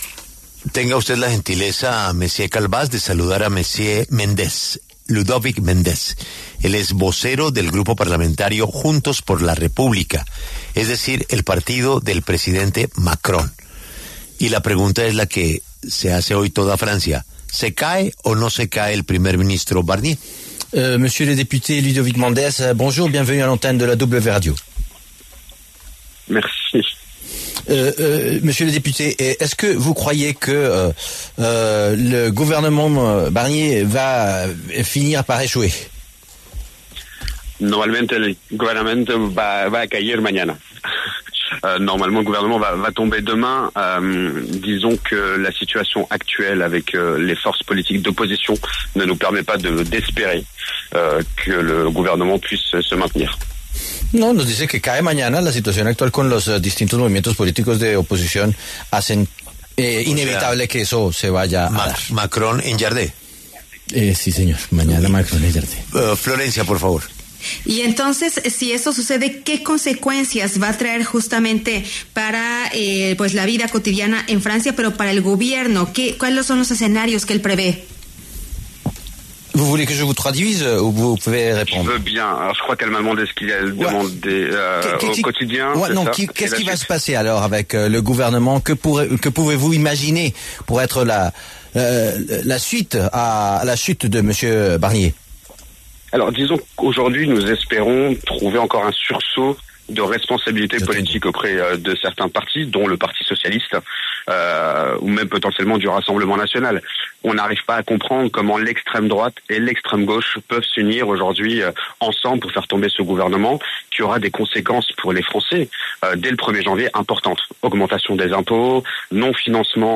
El diputado Ludovic Mendes, vocero del partido del presidente Macron, conversó con La W cobre la crisis política en Francia y la posible caída del primer ministro Michel Barnier.